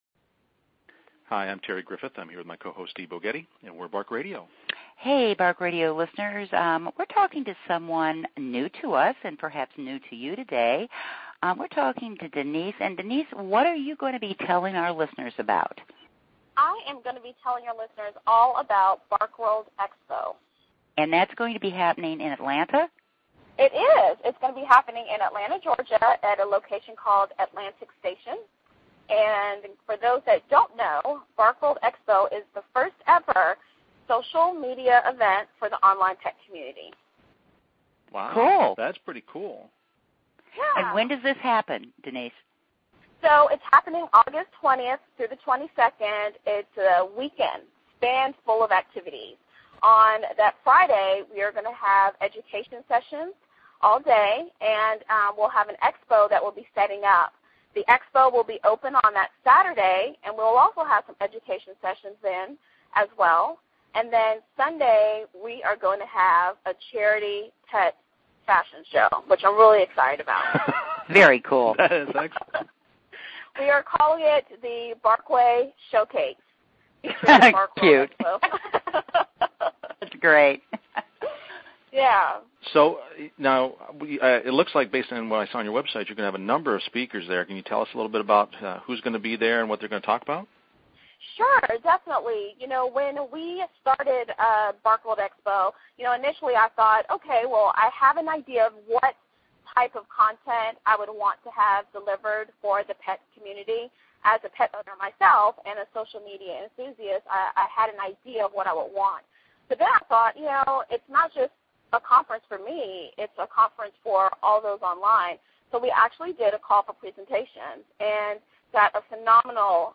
This entry was posted on Tuesday, June 15th, 2010 at 7:34 am and is filed under interview.